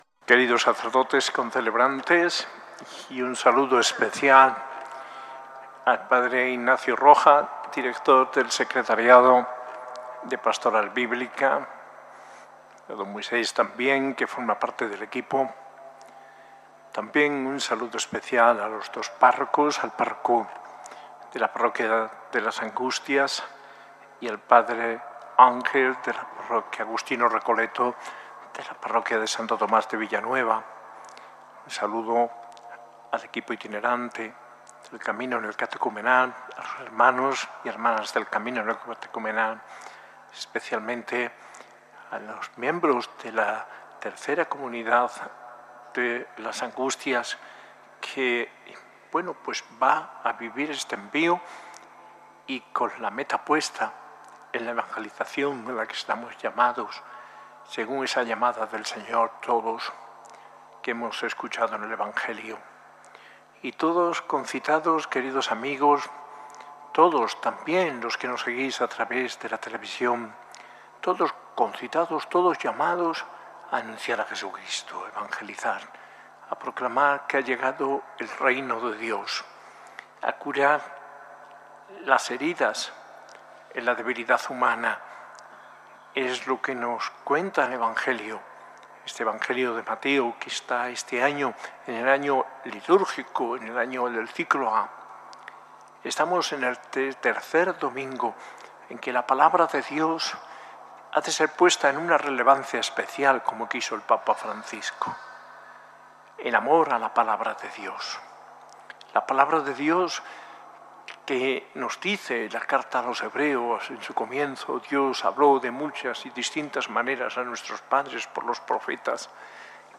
Homilía de D. José María Gil Tamayo en la Eucaristía celebrada en la Catedral de Granada en el Domingo de la Palabra de Dios y último día del Octavario de oración por la unidad de los cristianos, y la participación de la Tercera Comunidad de la parroquia de las Angustias con el Camino Neocatecumenal, el 25 de enero de 2026.